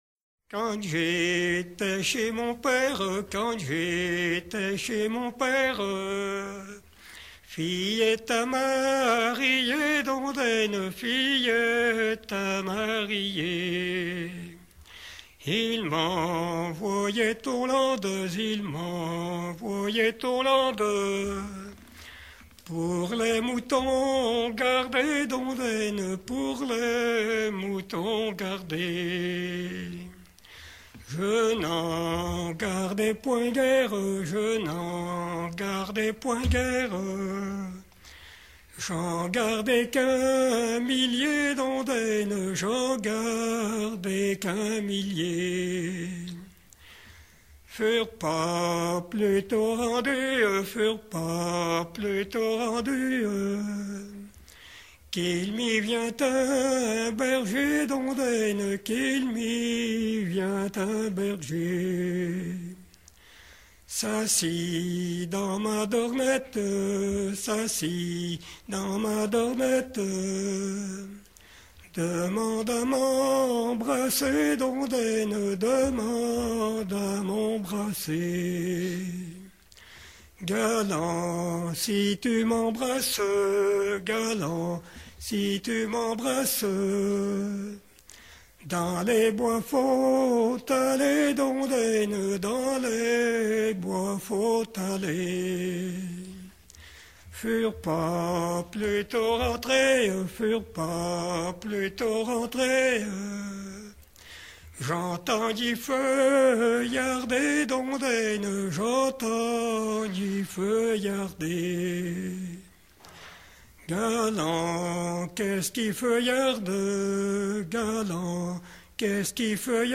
Saint-Georges-de-Montaigu
Genre laisse